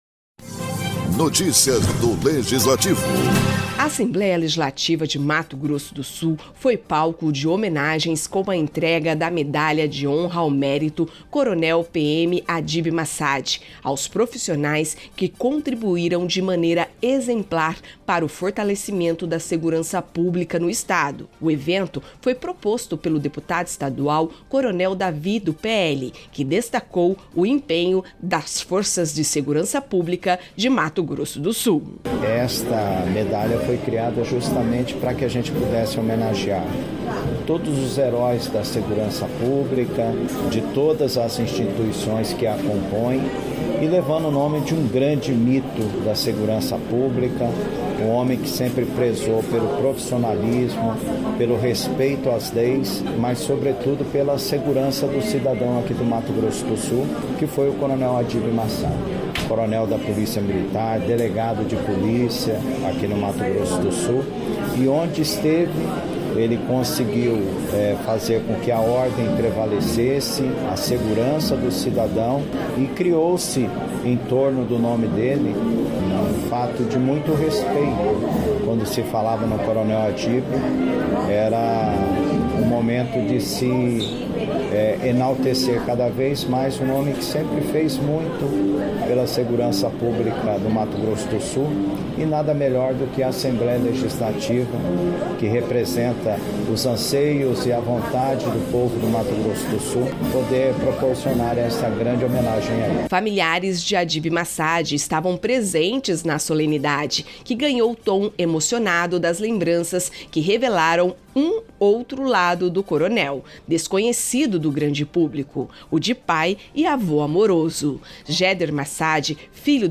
A solenidade foi proposta pelo deputado estadual Coronel David (PL) com a entrega da Medalha de Honra ao Mérito Coronel PM Adib Massad.